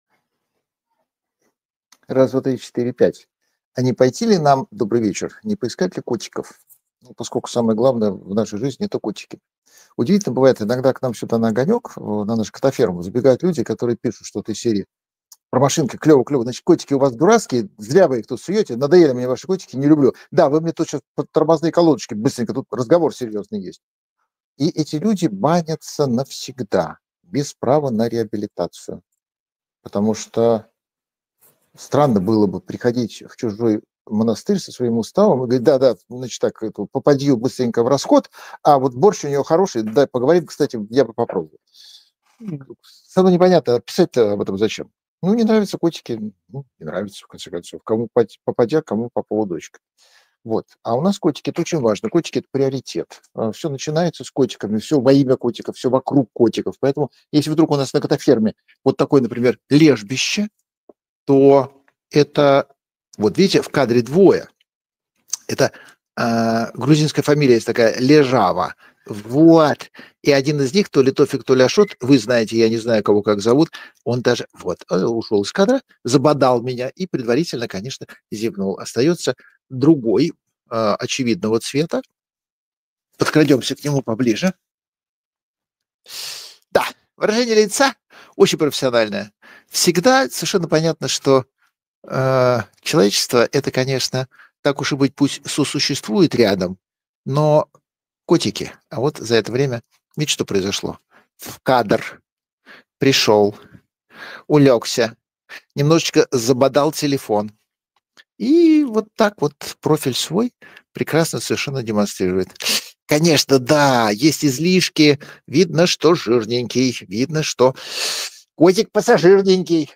Прямая трансляция